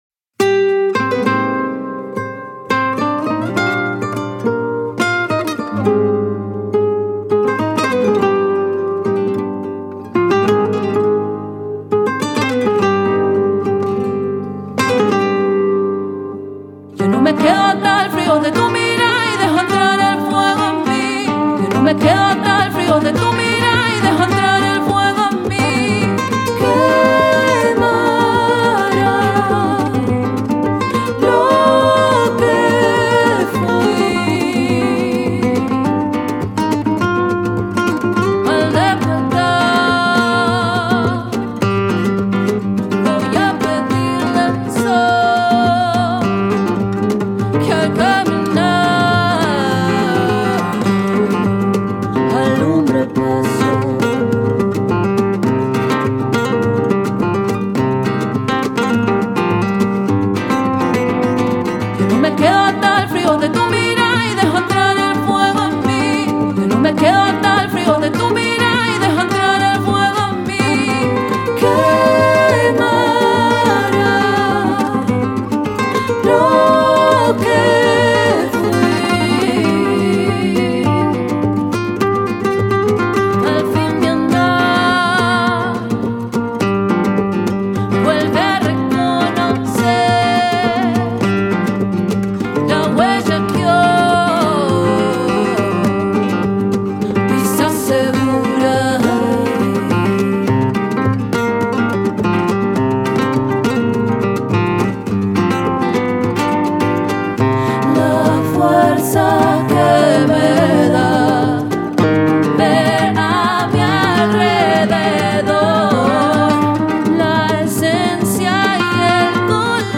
in tradional Flamenco style.
im traditionellem Flamenco-Stil.
Vocal performance
Guitar